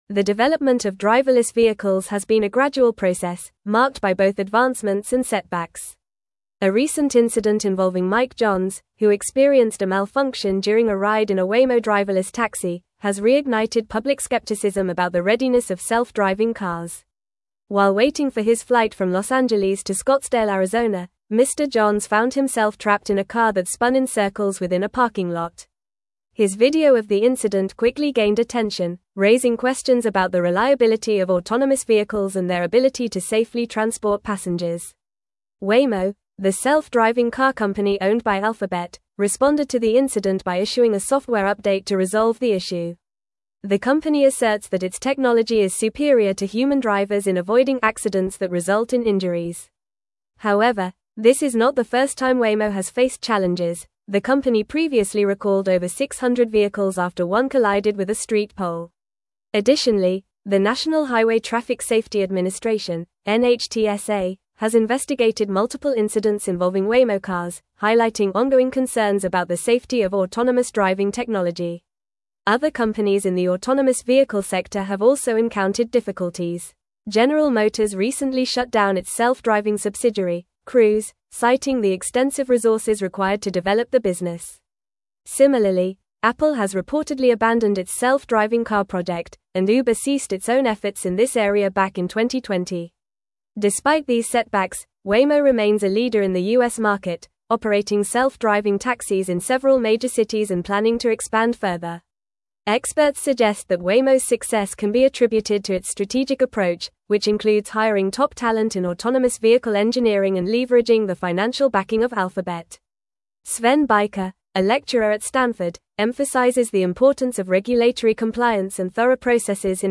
Fast
English-Newsroom-Advanced-FAST-Reading-Challenges-Persist-in-the-Adoption-of-Driverless-Vehicles.mp3